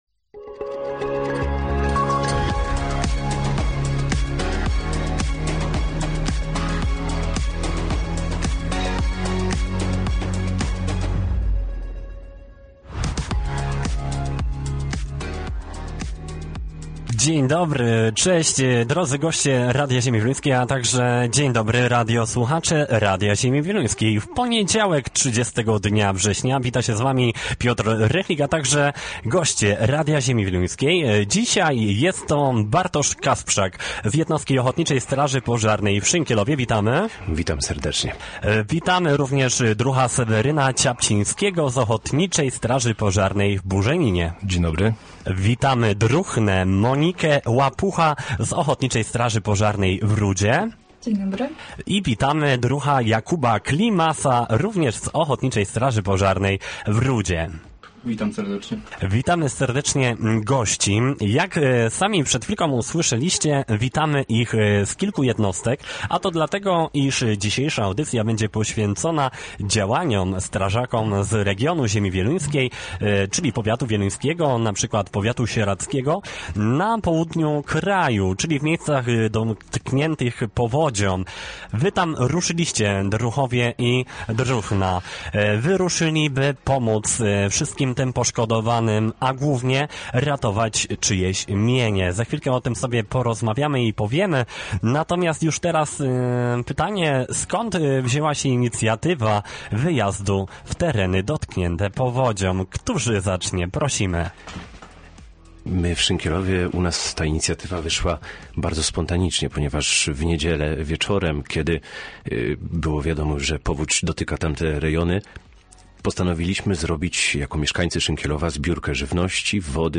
Gośćmi Radia ZW byli druhna i druhowie, którzy jako wolontariusze pomagali w miejscach powodzi na południu kraju